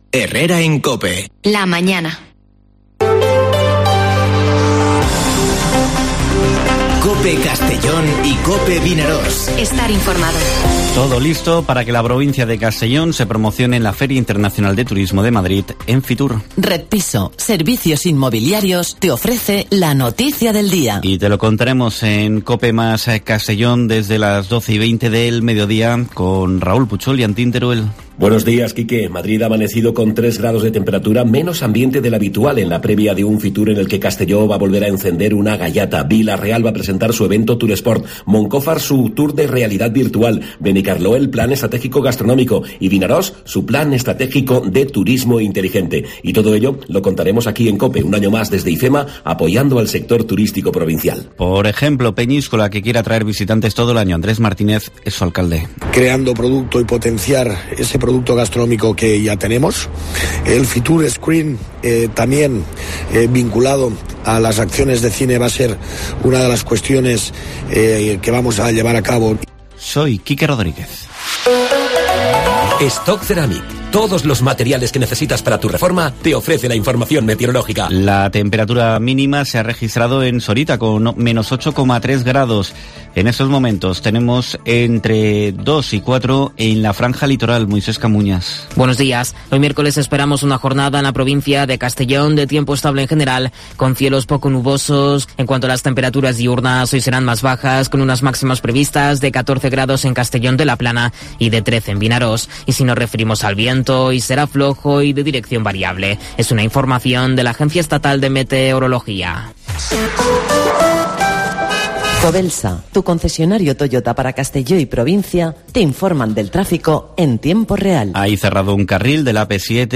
Informativo Herrera en COPE en la provincia de Castellón (19/01/2022)